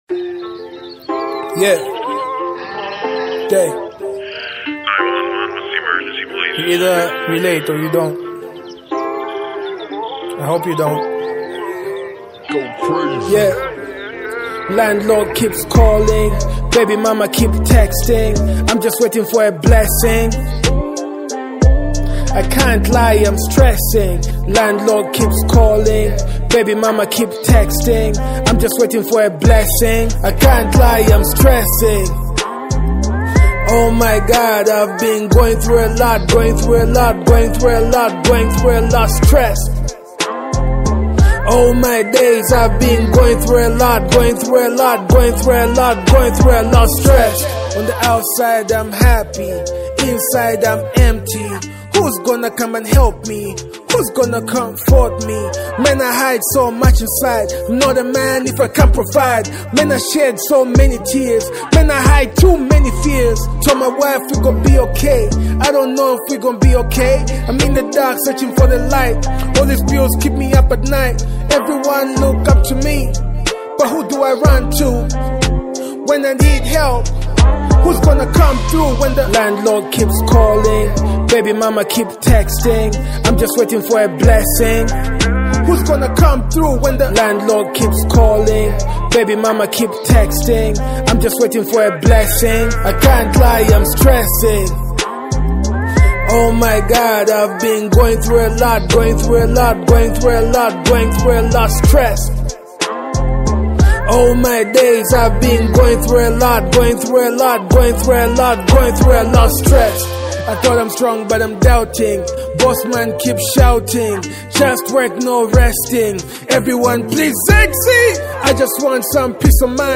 delivers a raw and honest performance
features introspective lyrics and a compelling beat